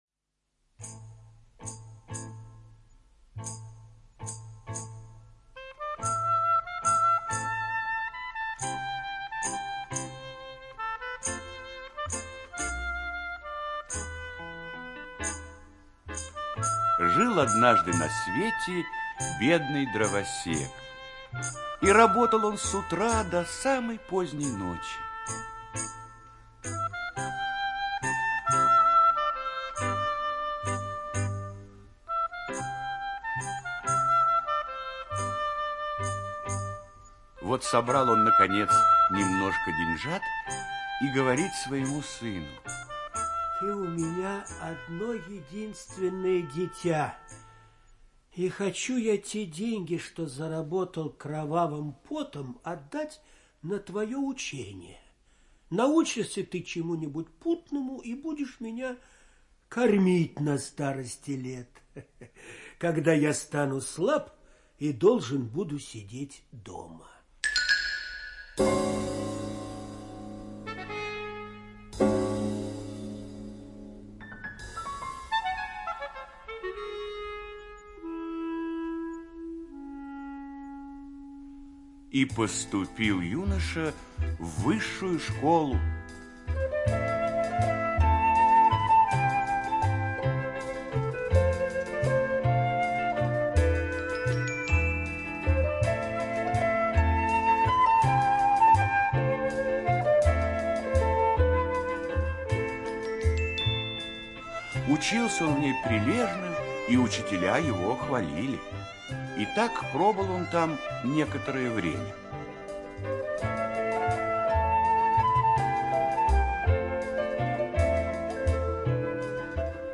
Дух в бутылке - аудиосказка братьев Гримм. Сказка о том, что чудо помогает в миг разбогатеть бедному дровосеку и его сыну.